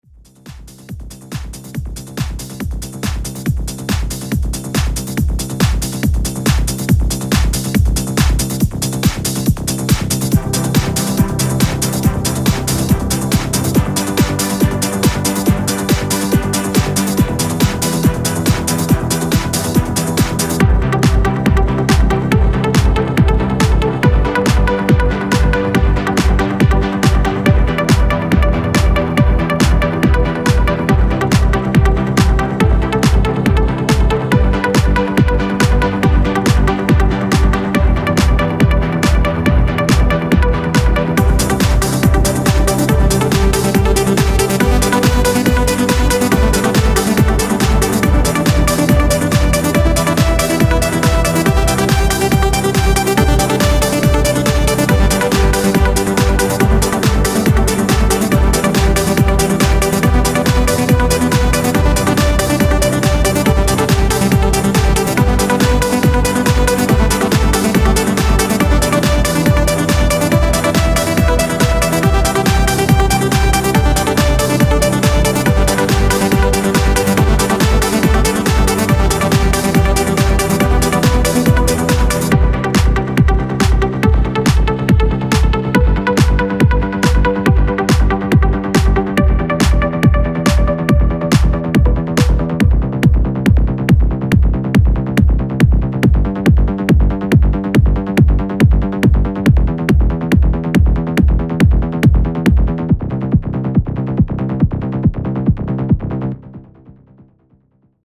soft trance
I gotta fix that bassline..
the patch I used for it has a built in kick which interferes with everything and the saw melody at the end doesn't loop well x3